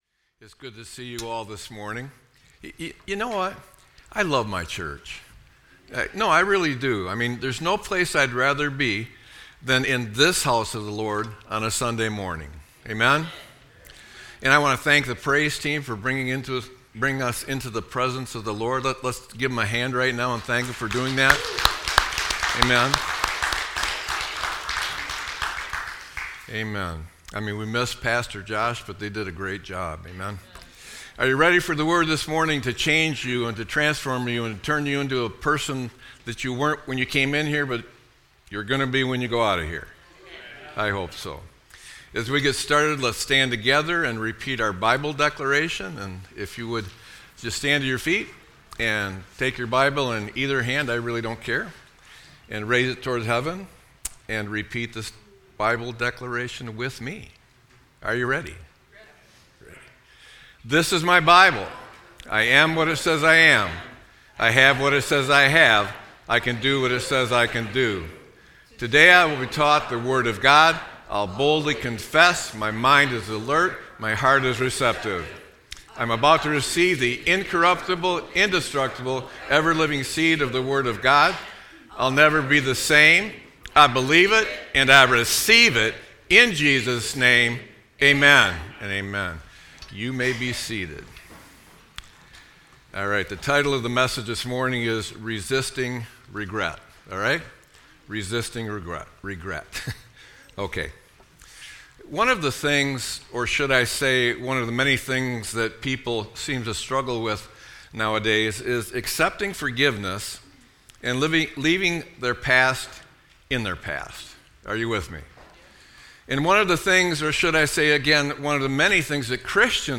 Sermon-8-31-25.mp3